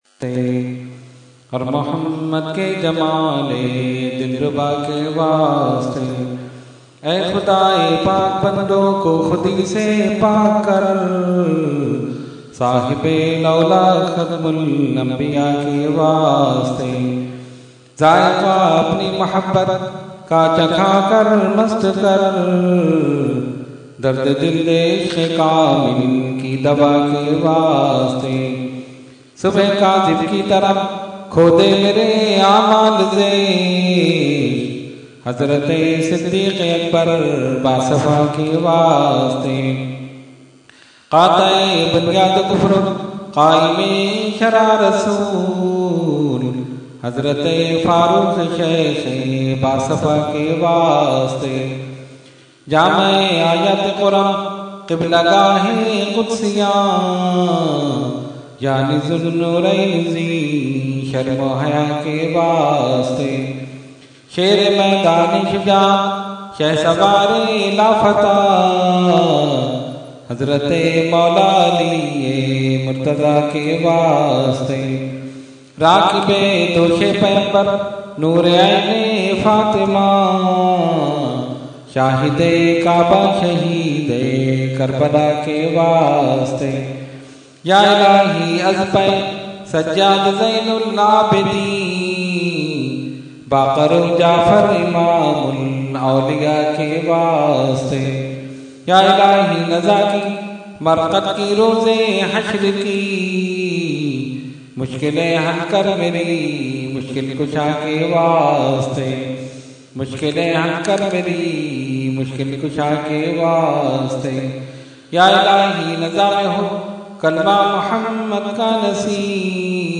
Category : Dua | Language : UrduEvent : Subhe Baharan 2015